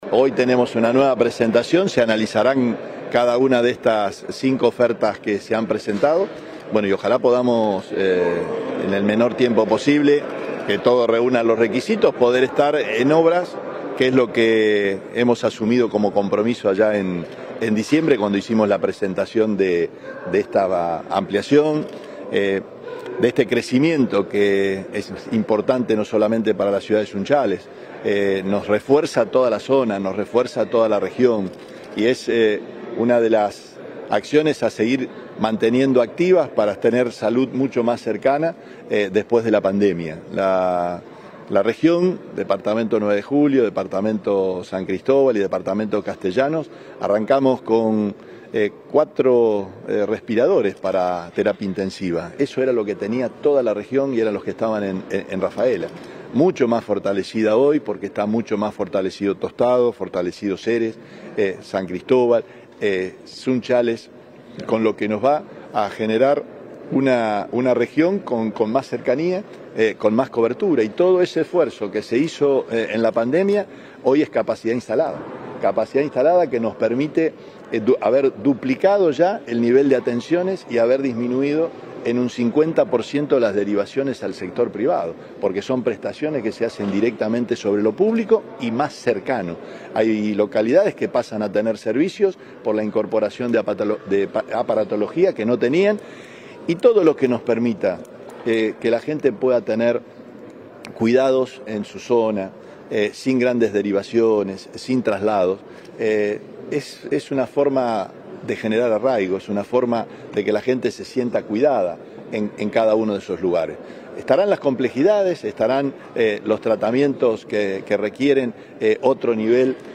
Declaraciones Perotti